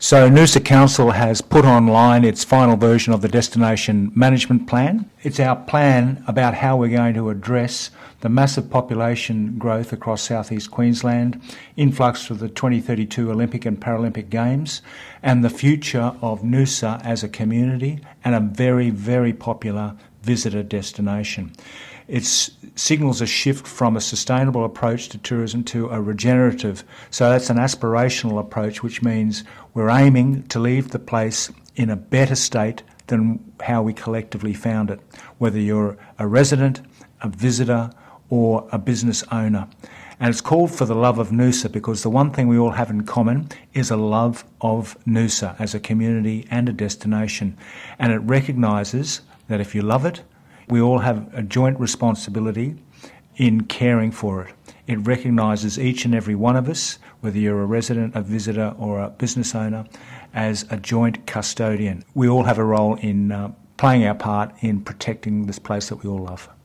Noosa Mayor Frank Wilkie on the DMP, now live on Council's website: